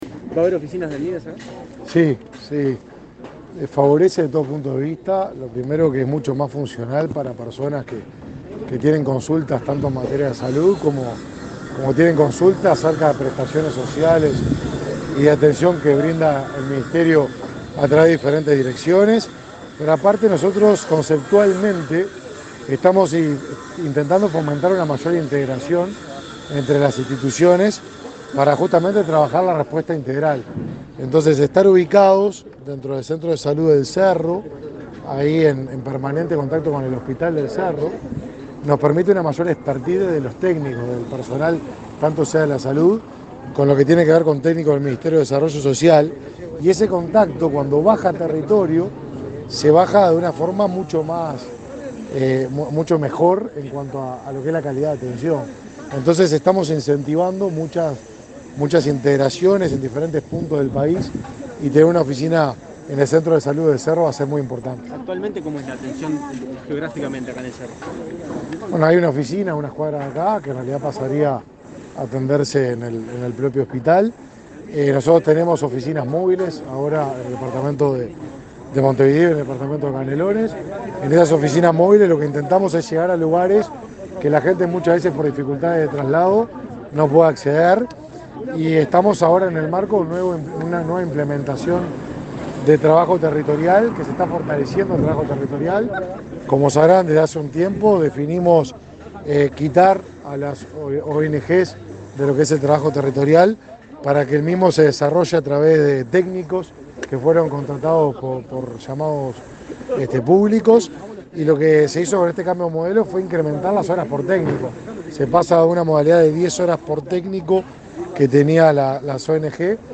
Declaraciones a la prensa del ministro de Desarrollo Social, Martín Lema
Este martes 21, el secretario de Presidencia, Álvaro Delgado; los ministros de Desarrollo Social, Martín Lema, y de Transporte y Obras Públicas, José Luis Falero, y el presidente de la Administración de los Servicios de Salud del Estado (ASSE), Leonardo Cipriani, visitaron las obras del hospital del Cerro, en Montevideo. Luego, Lema dialogó con la prensa.